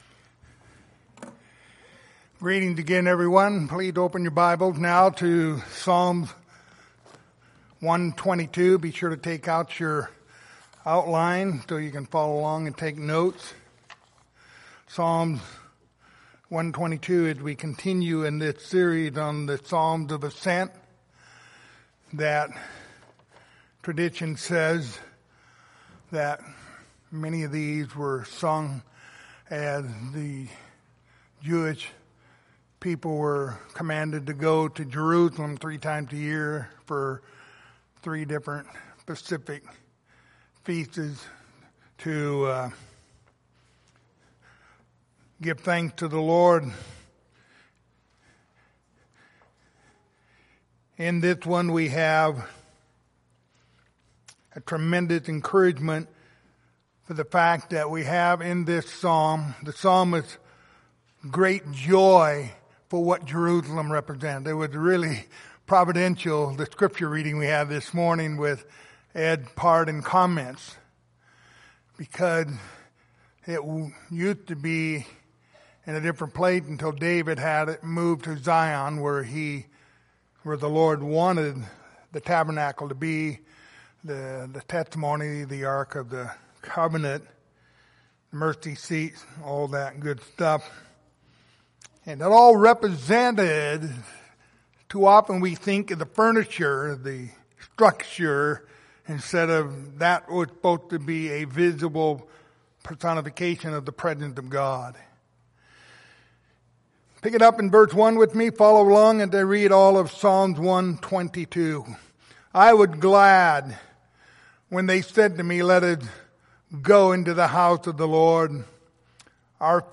Passage: Psalms 122:1-9 Service Type: Sunday Morning